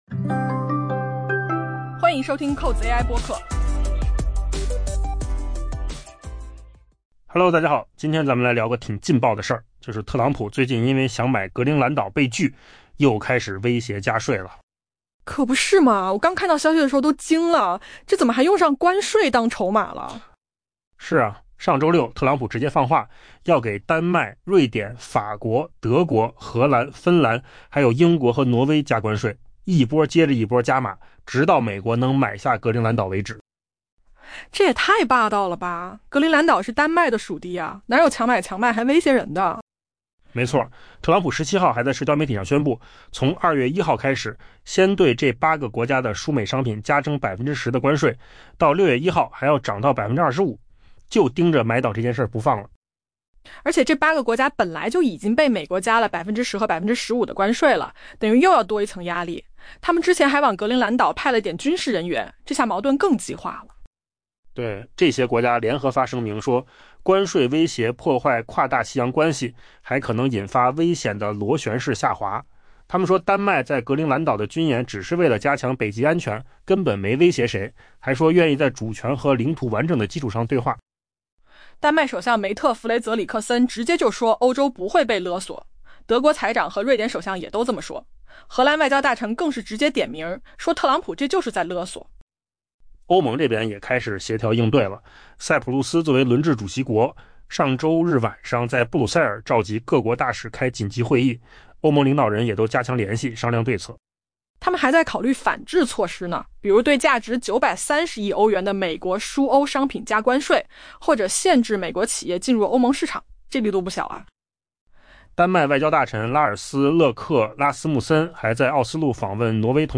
AI 播客：换个方式听新闻 下载 mp3 音频由扣子空间生成 包括德国和法国在内的主要欧盟国家上周日谴责美国总统特朗普就格陵兰岛问题发出的关税威胁是 「勒索」，法国提议以一系列未经实践的经济反制措施予以回应。